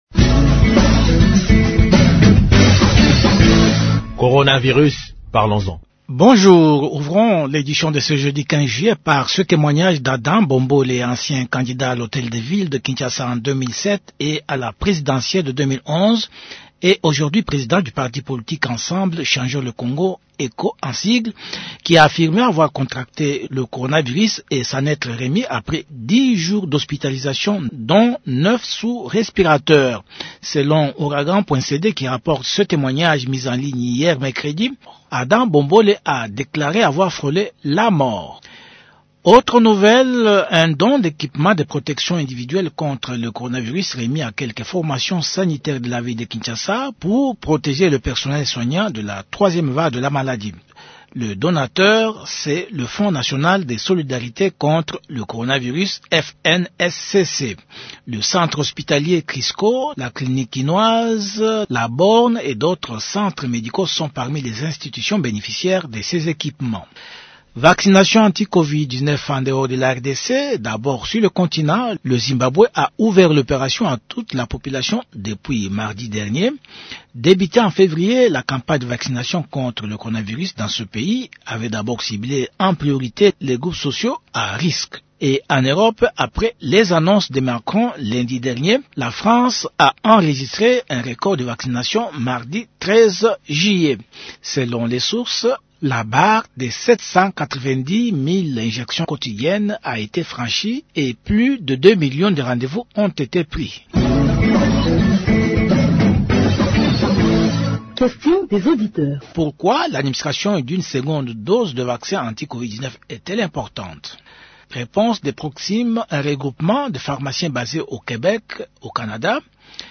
Je vous quitte en vous laissant suivre un extrait du message du gouverneur lu par son porte-parole